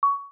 Listen to a 1121 Hz tone damping to 10% at 150 milliseconds